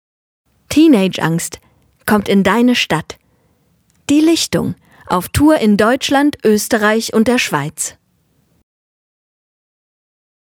Profi - Sprecherin mit junger, variabler Stimme von naiv bis kompetent
Kein Dialekt
Sprechprobe: Sonstiges (Muttersprache):